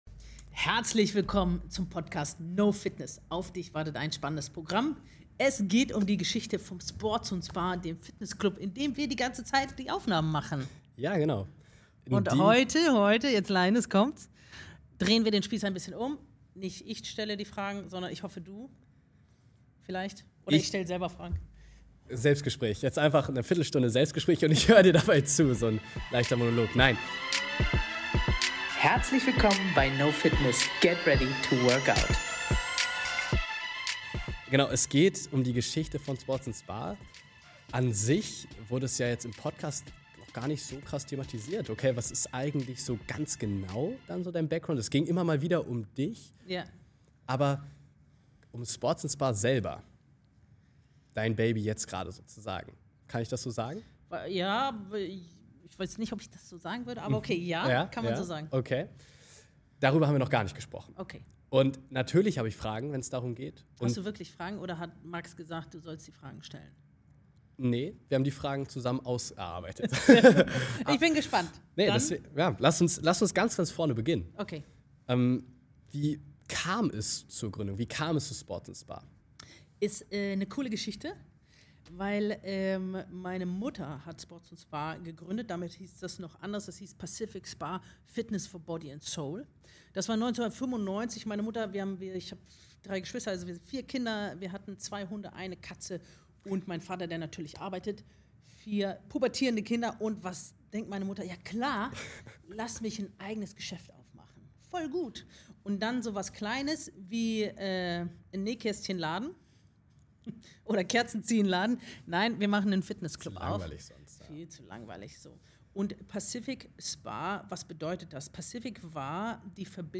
In dieser Folge sprechen wir über die Entstehung und Entwicklung von Sports & Spa – dem Fitnessclub, in dem der Podcast aufgenommen wird.